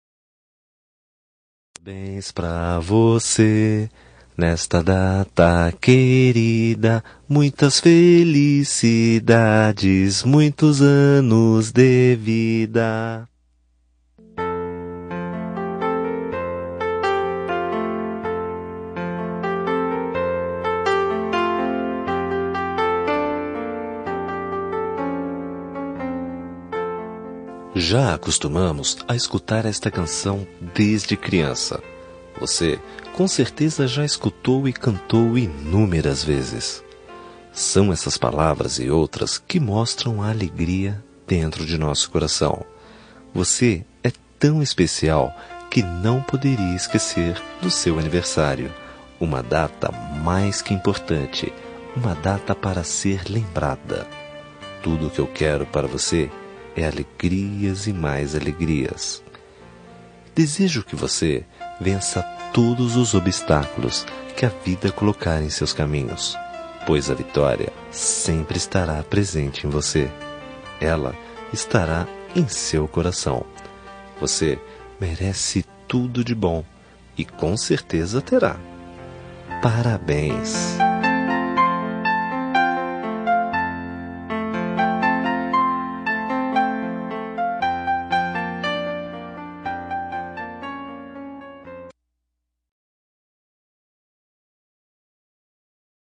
Aniversário de Pessoa Especial – Voz Masculina – Cód: 1911 – Parabéns